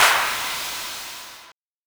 clap2.ogg